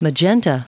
Prononciation du mot : magenta